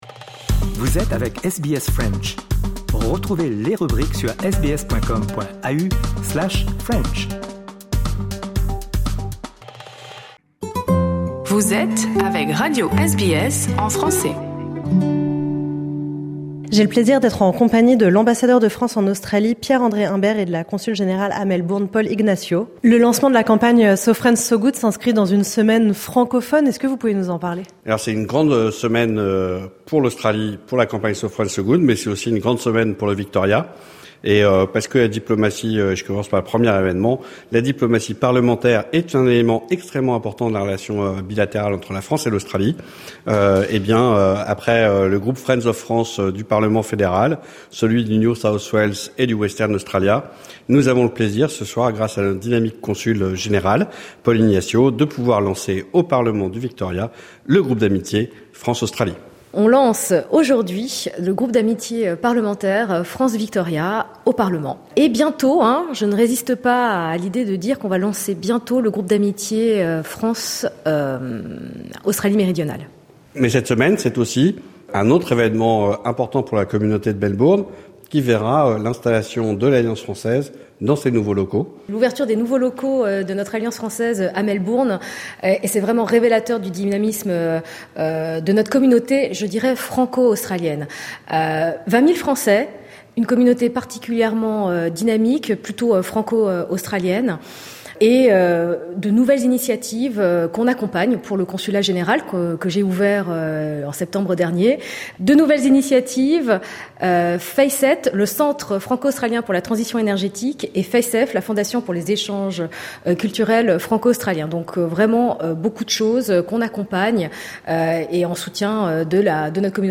Rencontre avec l’Ambassadeur de France en Australie, Pierre-André Imbert, et la Consule-Générale à Melbourne, Paule Ignatio, pour évoquer les différents événements dynamisant la relation franco-australienne cette semaine à Melbourne.